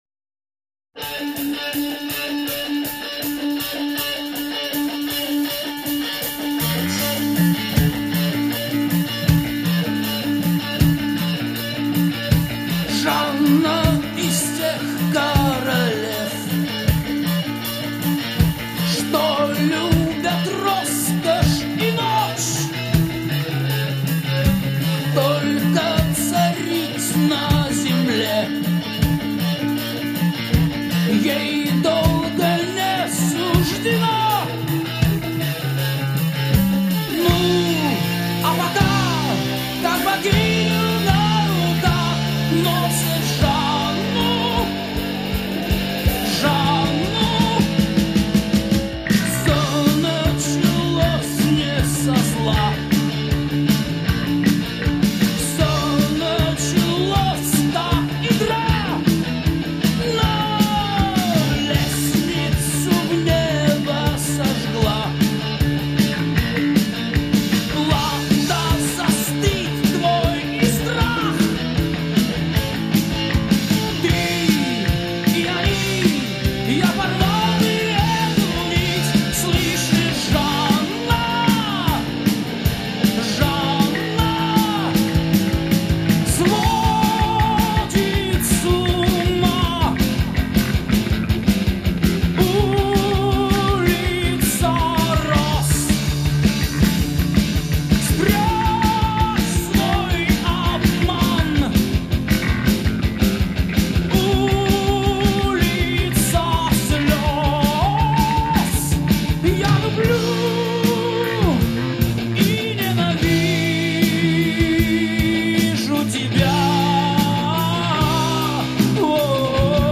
Heavy metal Метал
Hard Rock Speed Metal